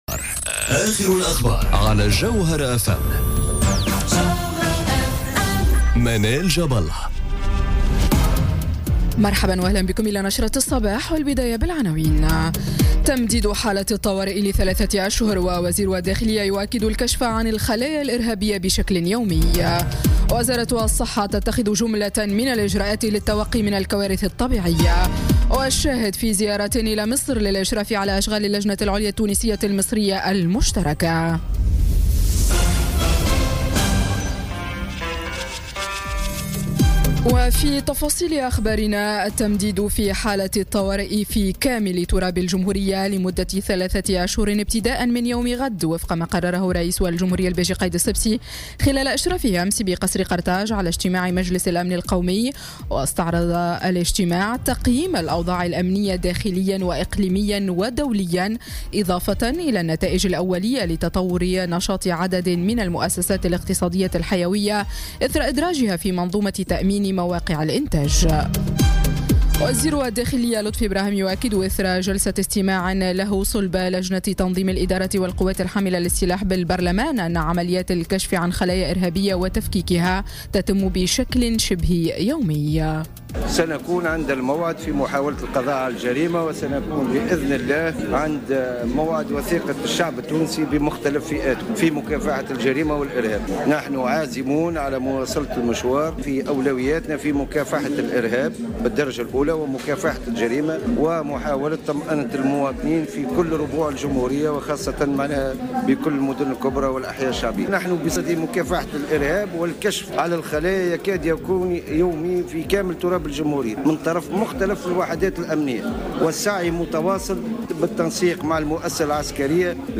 نشرة أخبار السابعة صباحا ليوم السبت 11 نوفمبر 2017